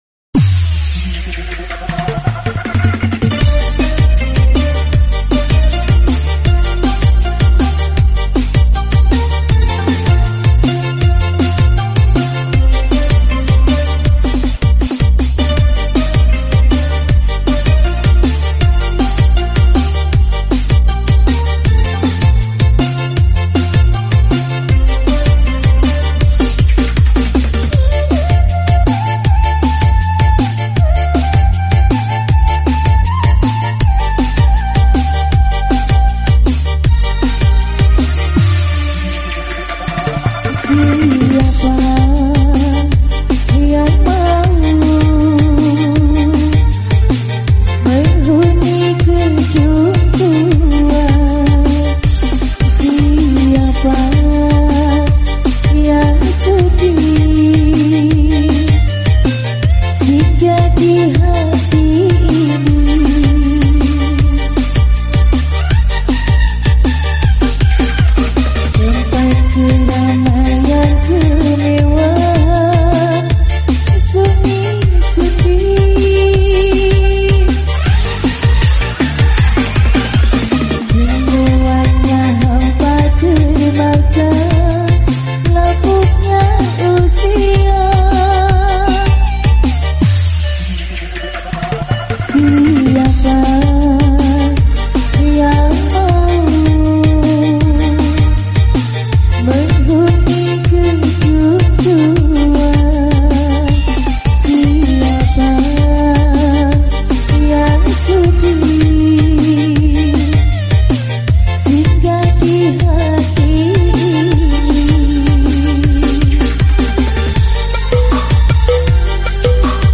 full bass